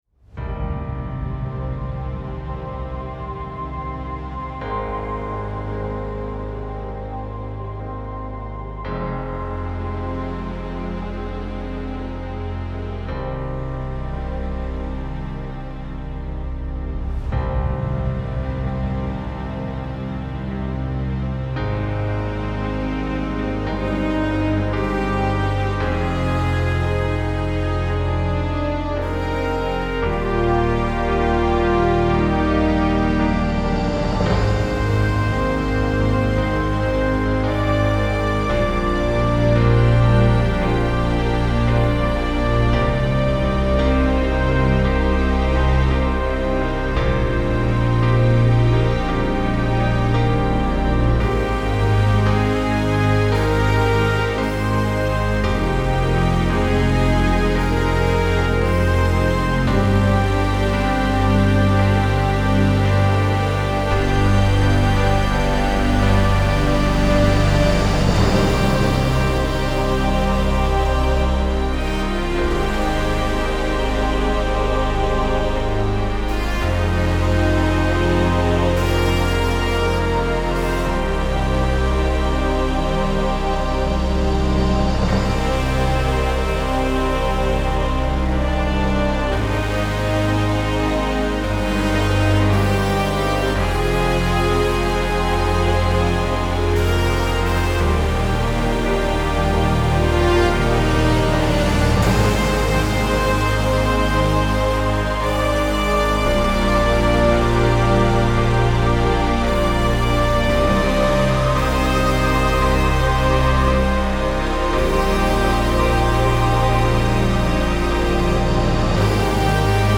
long no rhythm Version